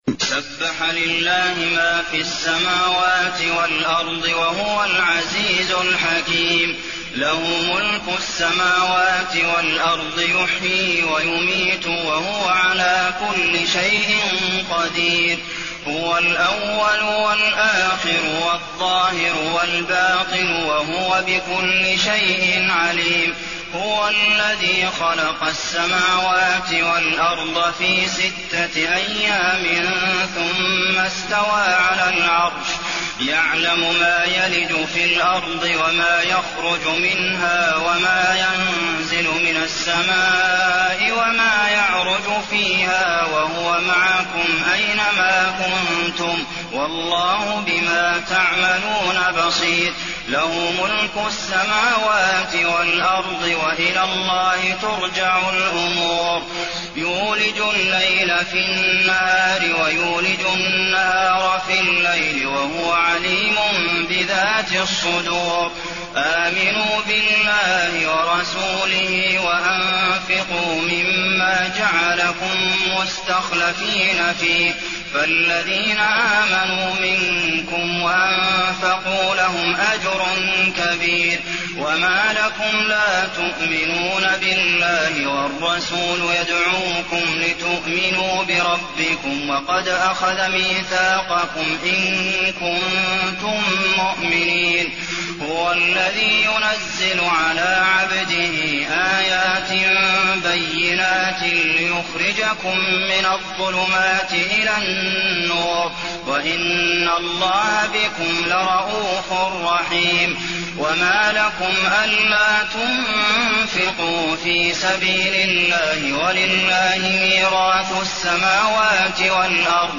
المكان: المسجد النبوي الحديد